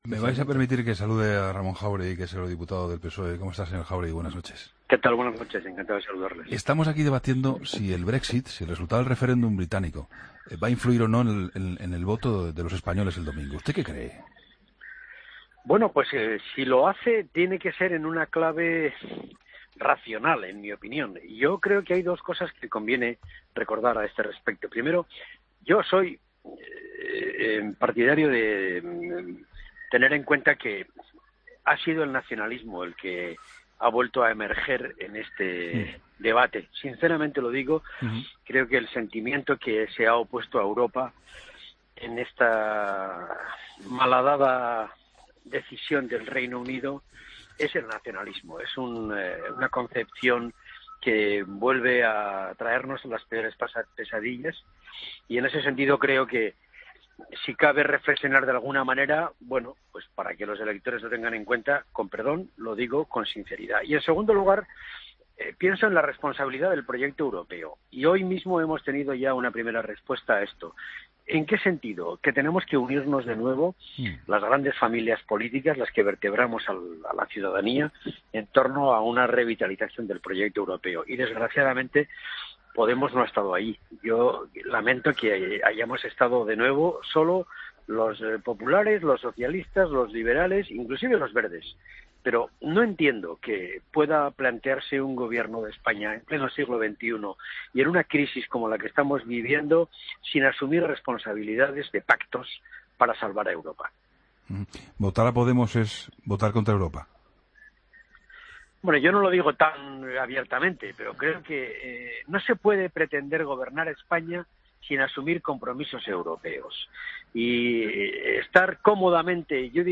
Entrevista a Ramón Jáuregui, eurodiputado socialista, en 'La Linterna'